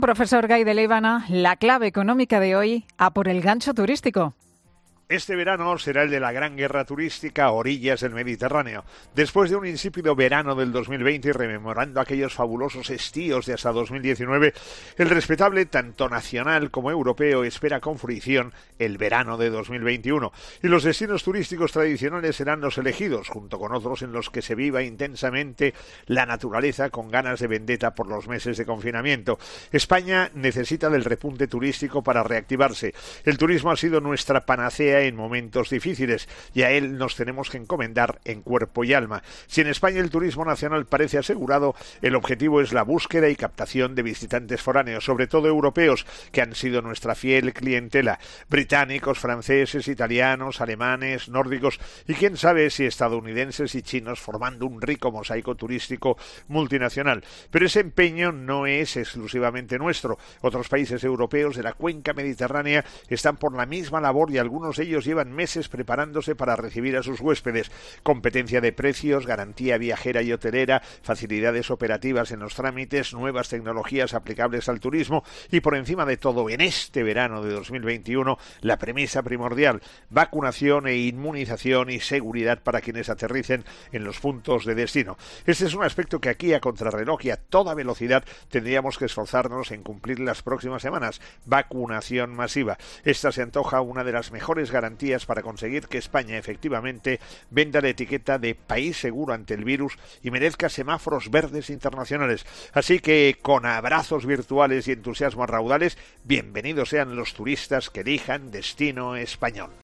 El profesor José María Gay de Liébana analiza en 'Herrera en COPE' las claves económicas del día.